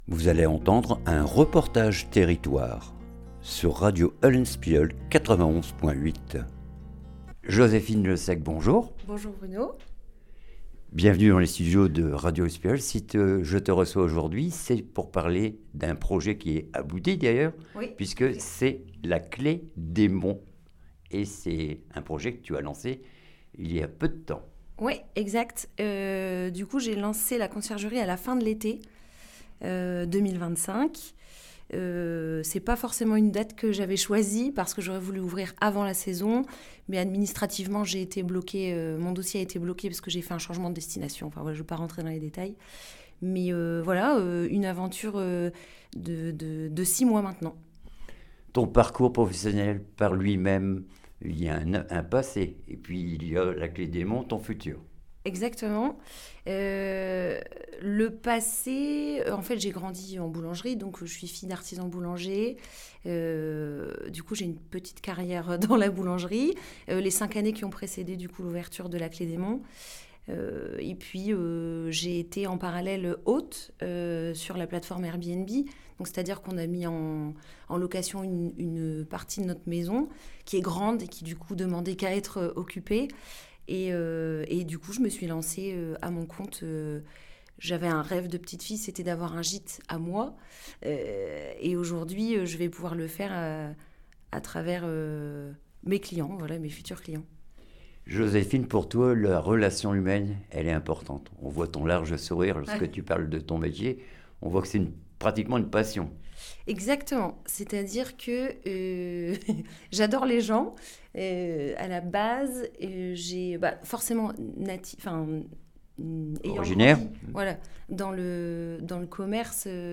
REPORTAGE TERRITOIRE LA CLEF DES MONTS